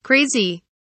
crazy kelimesinin anlamı, resimli anlatımı ve sesli okunuşu